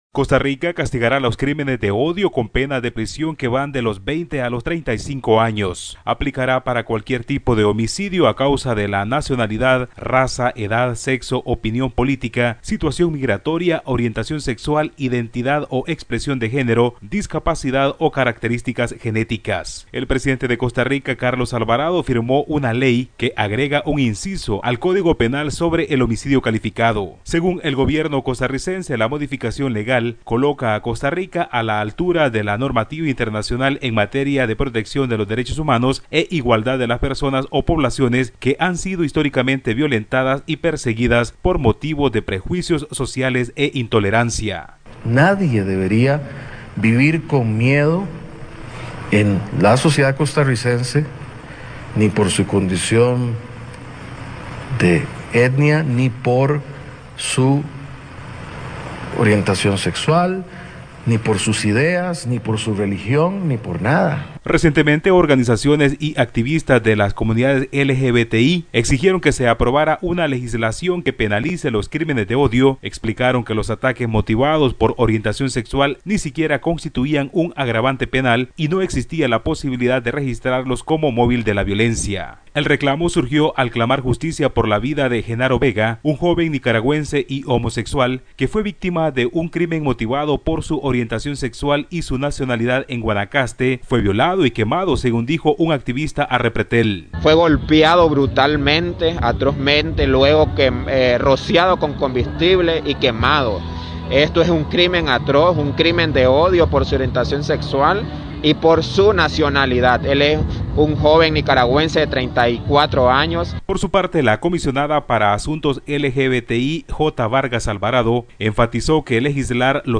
Los asesinatos de odio eran calificados en Costa Rica como homicidios simples, debido a que existía un vacío en el Código Penal, pero ahora la legislación estableció prisión de entre 20 y 35 años a quien lesione, agreda o dé muerte por razones de raza, edad, nacionalidad, opinión política, discapacidad, orientación sexual o religión. Escucha el informe del corresponsal de SBS Spanish en Latinoamérica